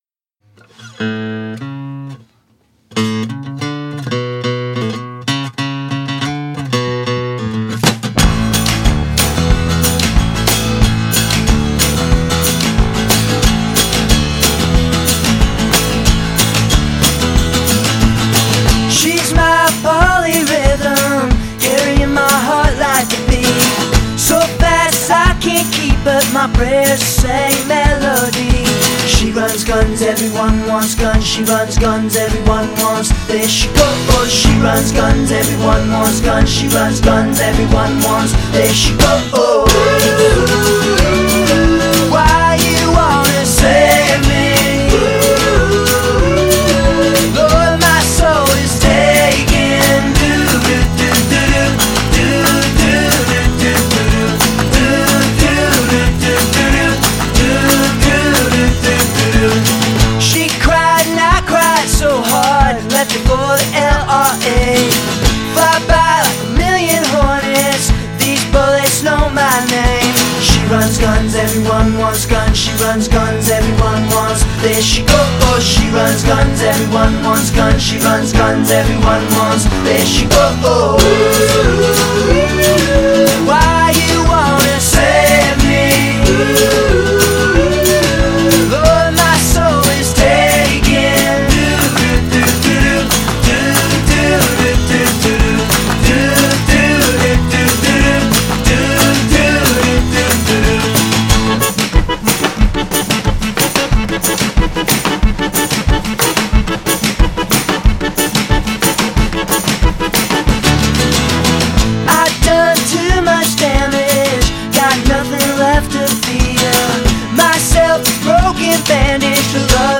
But it’s 1972 Paul Simon so it’s cool.